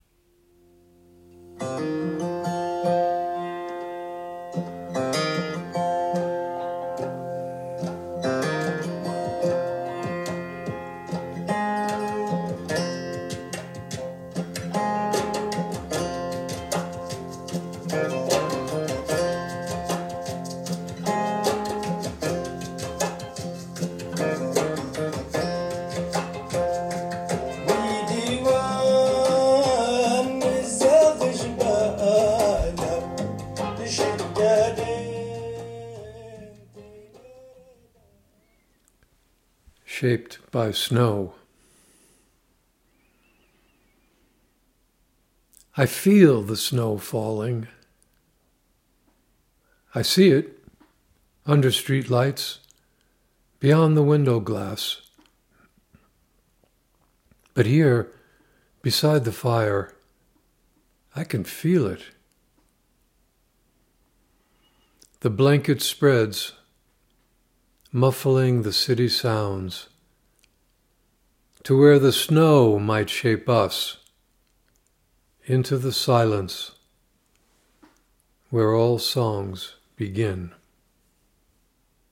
Reading of “Shaped by Snow” with music by Tinariwen